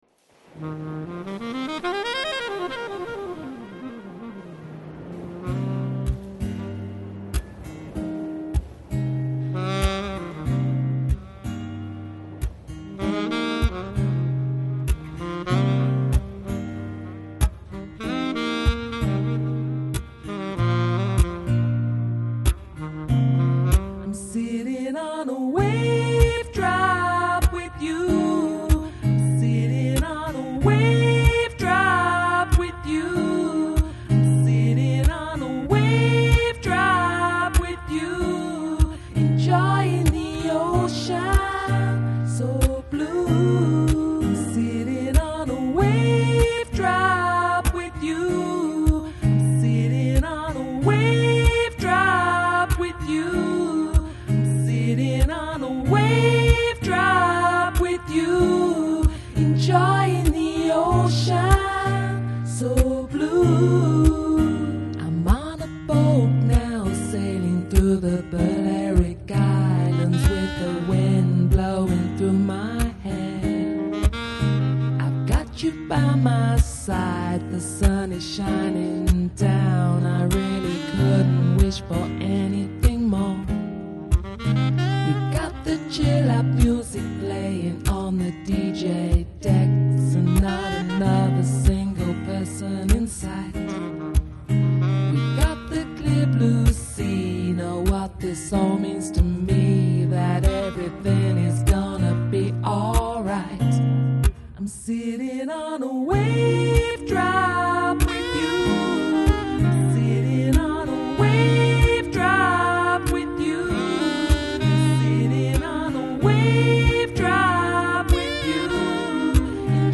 Electronic, Downtempo, Lounge, Chill Out Год издания